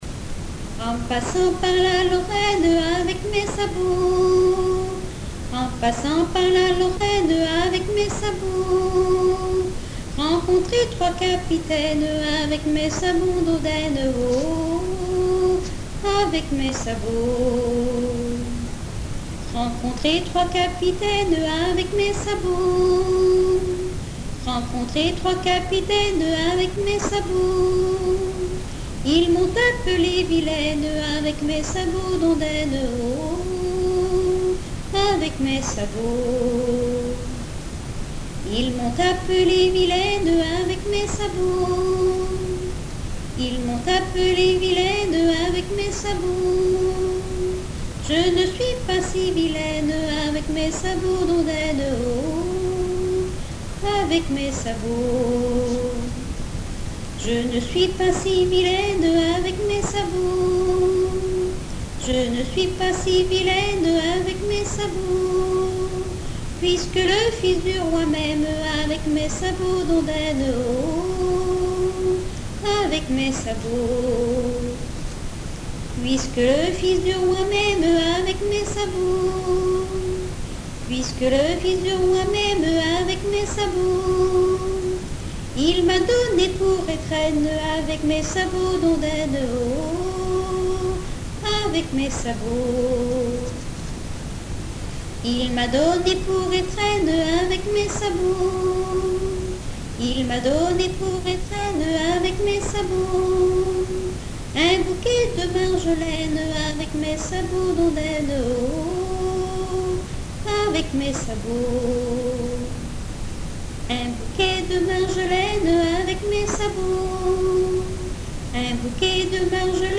Mémoires et Patrimoines vivants - RaddO est une base de données d'archives iconographiques et sonores.
chansons de variété et traditionnelles
Pièce musicale inédite